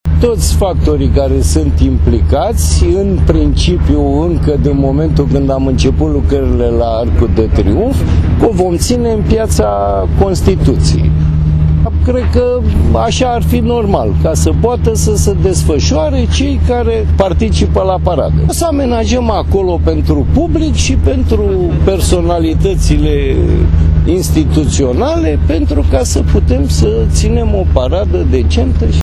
Afla mai multe detalii despre parada chiar de la Primarul Capitalei, Sorin Oprescu: